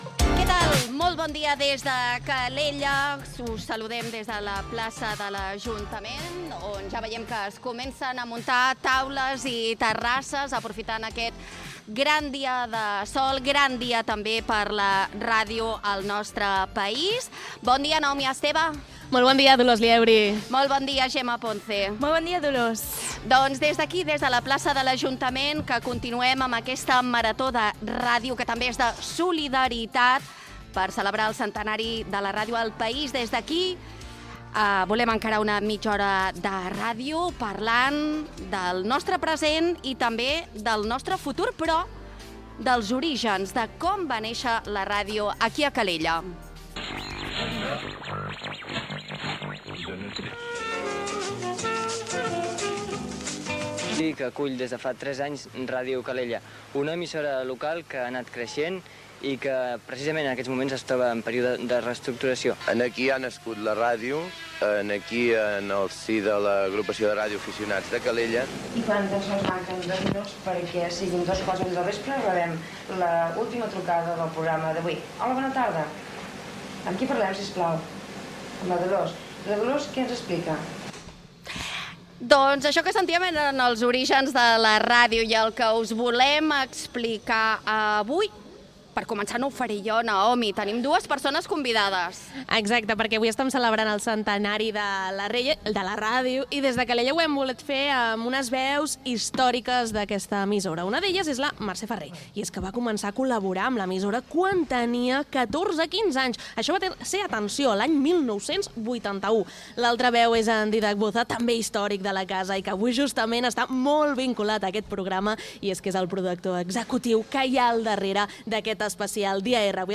Fragment del programa fet des de la plaça de l'Església de Calella. Recorden com va néixer la ràdio a Calella.
Entreteniment